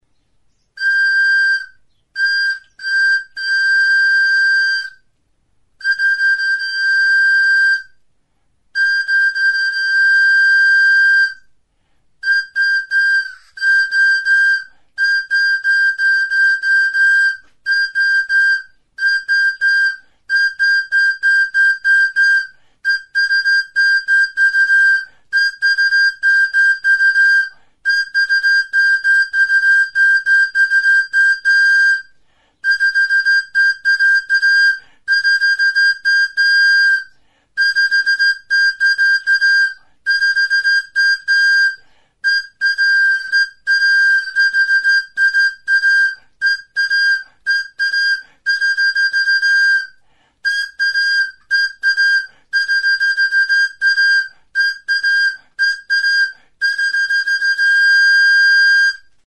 Instruments de musiqueTXILIBITU; SILBATO
Aérophones -> Flûtes -> Á Bec (á une main)
Enregistré avec cet instrument de musique.
Plastikozko txilibitu gorria da.